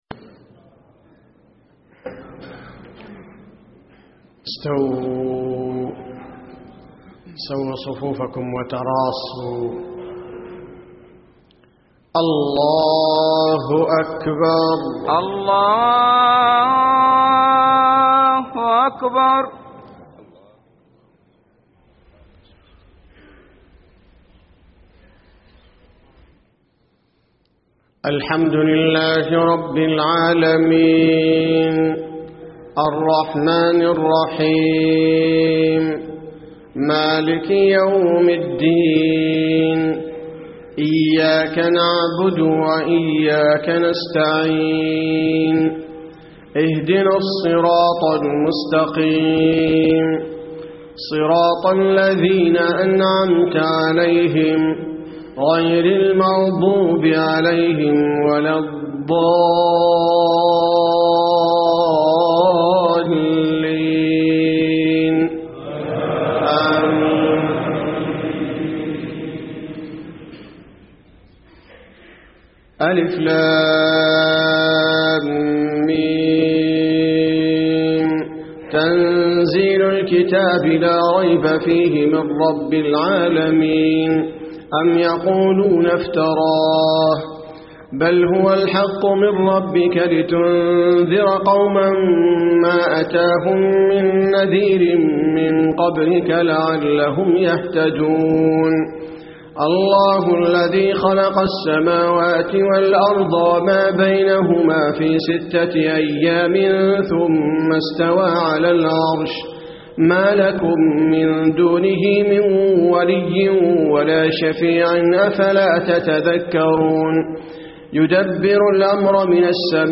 صلاة الفجر 9-6-1434 من سورتي السجدة و الإنسان > 1434 🕌 > الفروض - تلاوات الحرمين